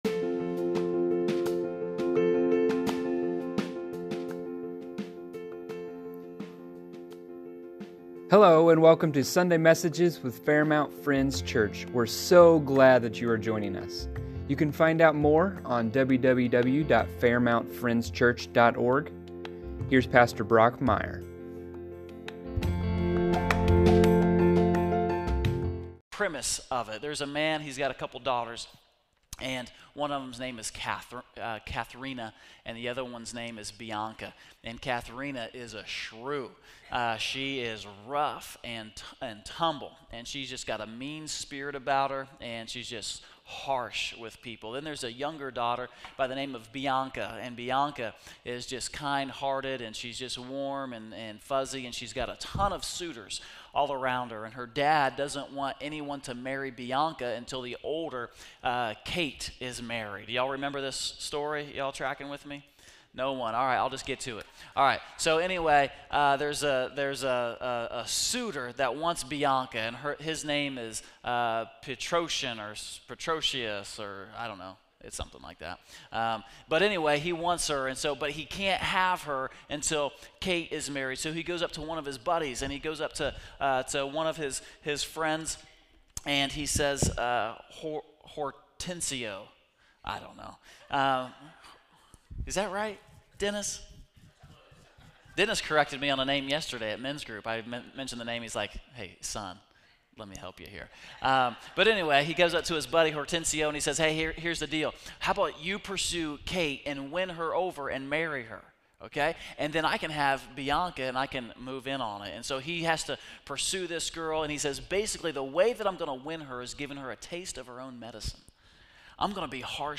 Sunday Messages | Fairmount Friends Church